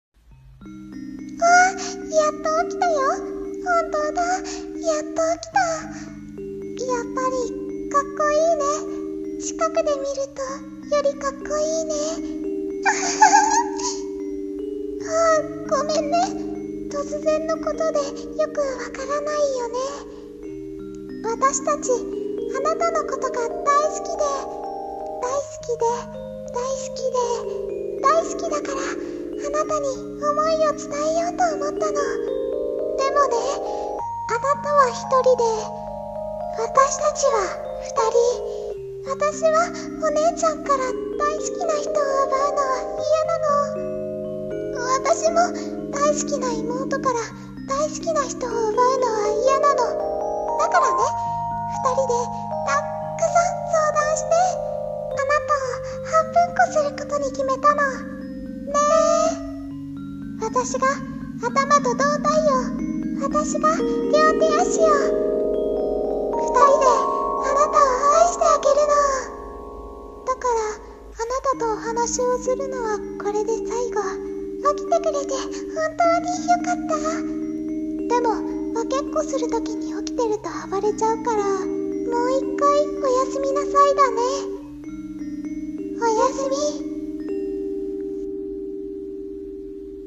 【声劇台本】ヤンデレ双子